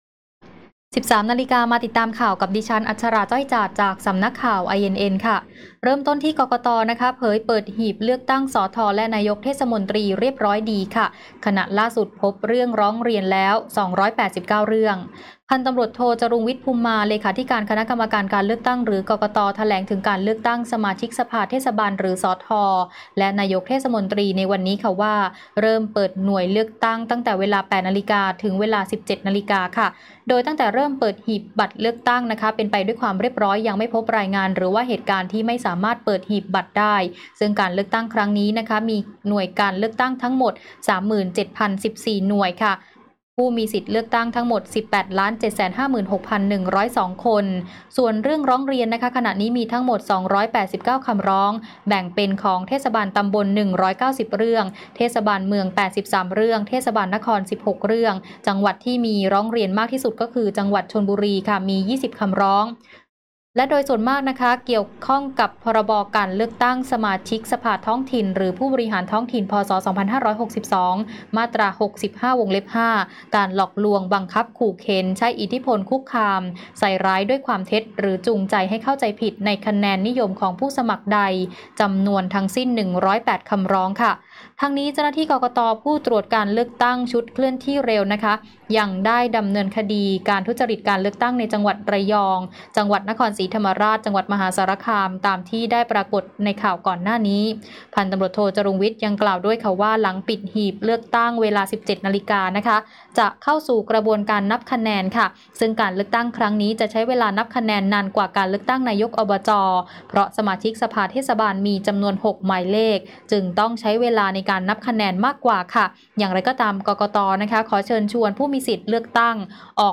ข่าวต้นชั่วโมง 13.00 น.